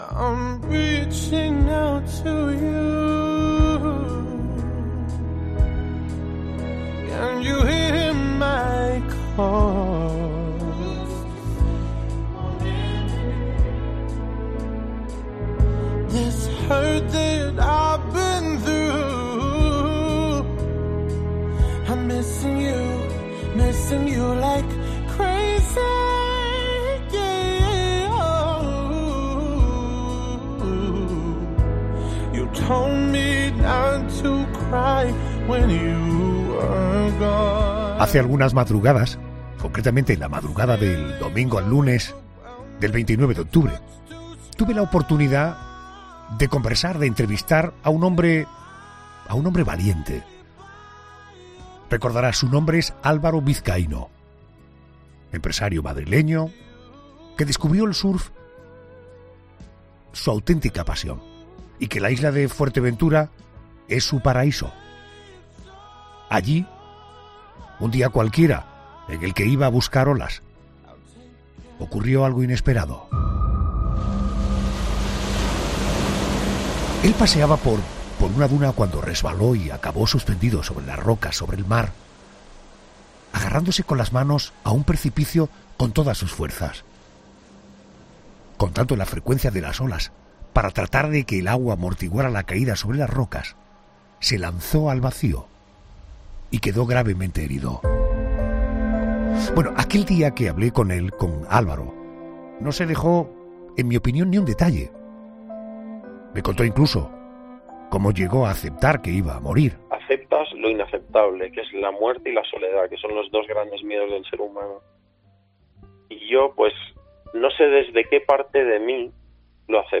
Segunda parte de la entrevista